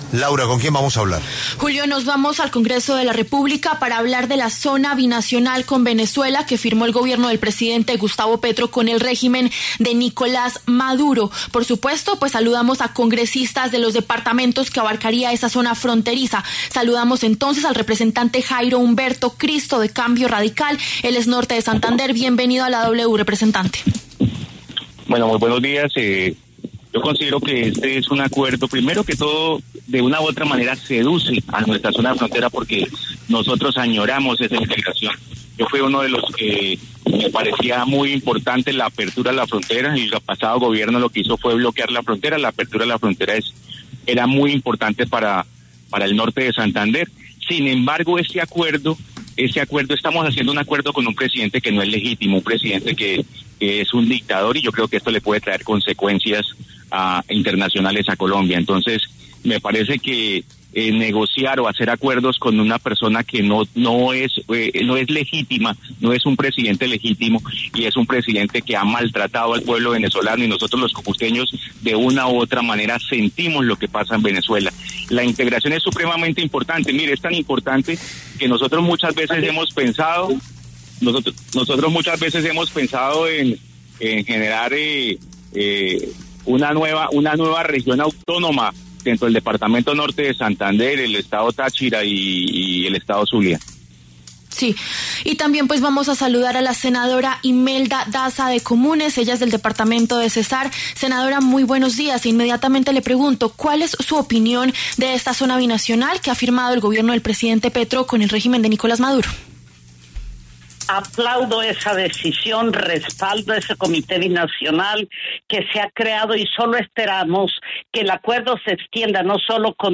Los congresistas Imelda Daza, de Comunes, y Jairo Humberto Cristo, de Cambio Radical, pasaron por los micrófonos de La W.